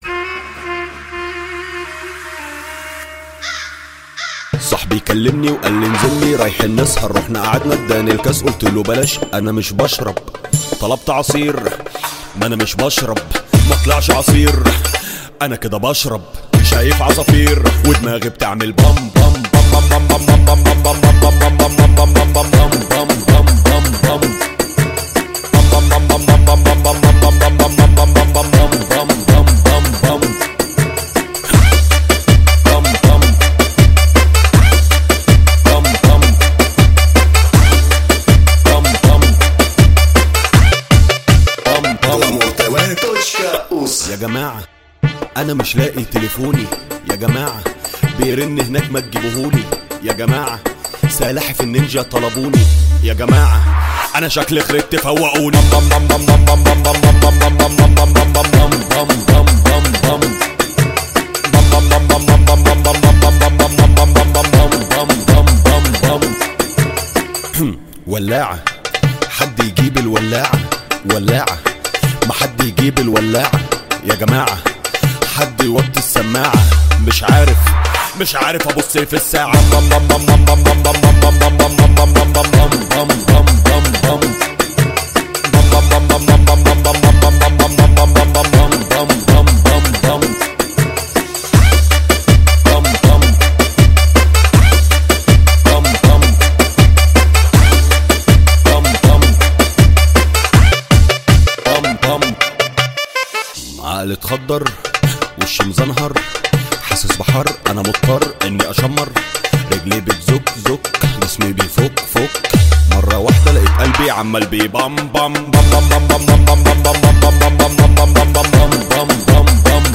ژانر: شوتی